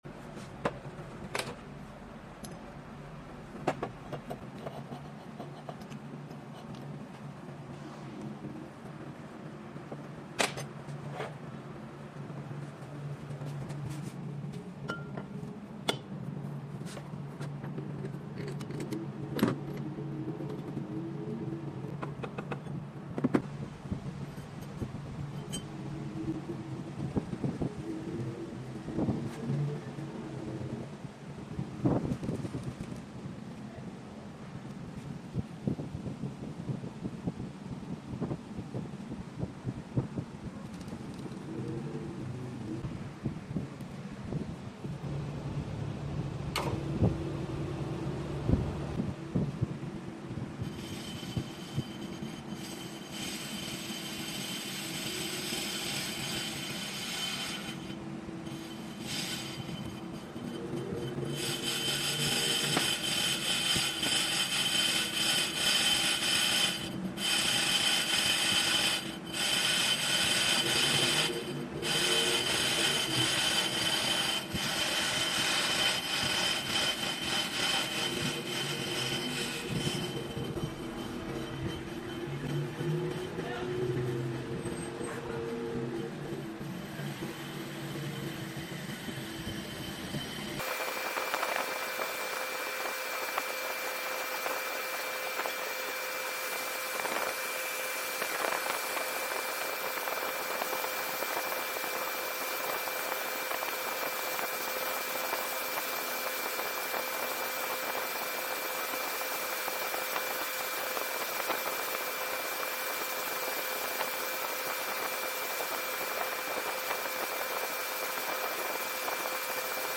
brake disc resurfacing sound effects free download